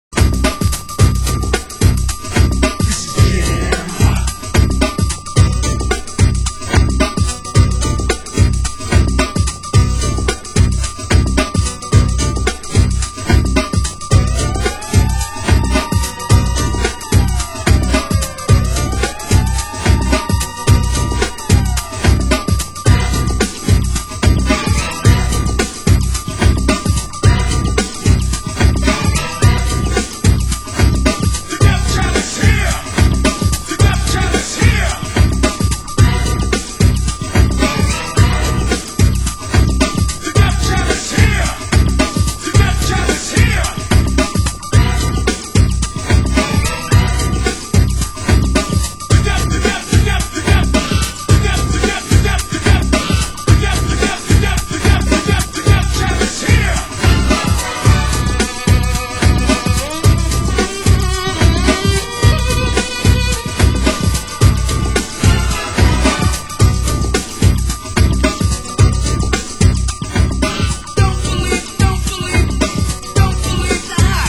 Genre: Warehouse